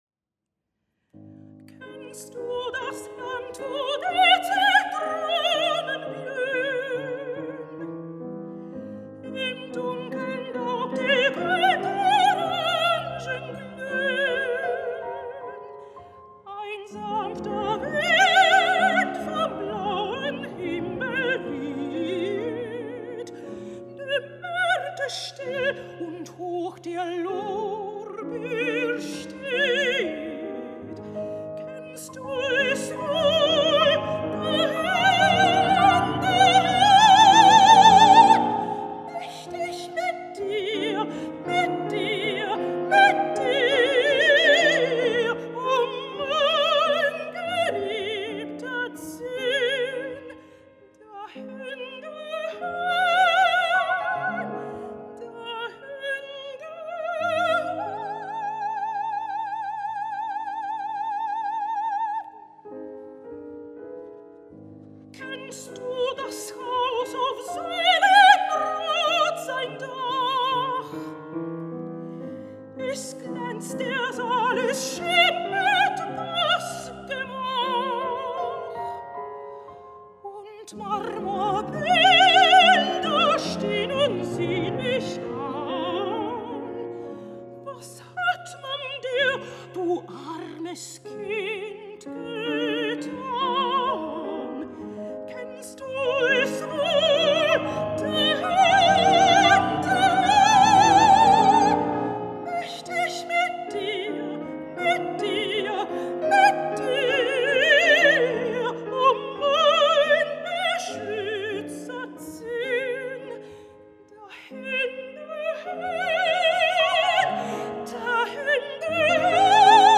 "Minjona" (Mignon's song) Created Year: 1914 Genre: Vocal chamber music Ilgums: 00:03:28 Instrumentation: voice, piano Composer: Alfrēds Kalniņš Text by J. W. Goethe (translated by R. Blaumanis).